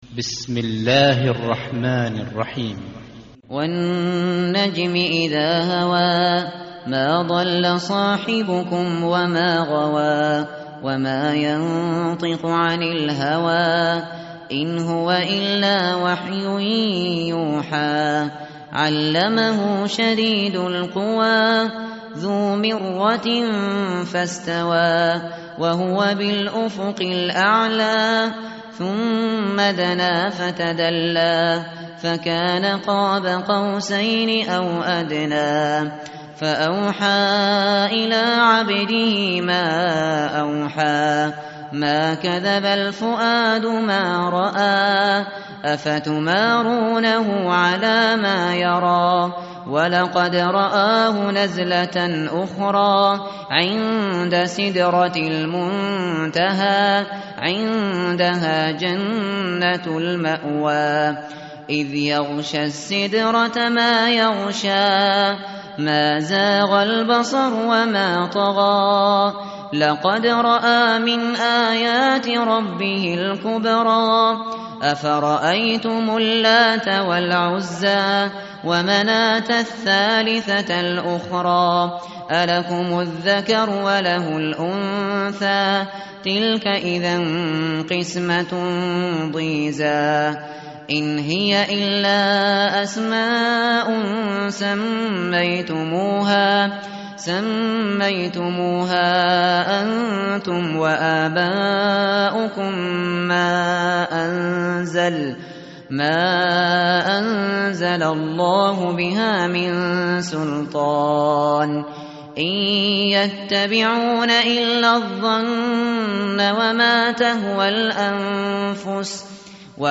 tartil_shateri_page_526.mp3